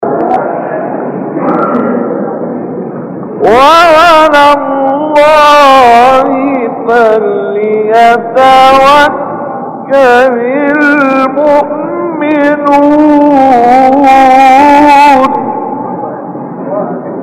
گروه شبکه اجتماعی: مقاطعی صوتی از تلاوت قاریان برجسته مصری ارائه می‌شود.
مقطعی از احمد محمد عامر در مقام چهارگاه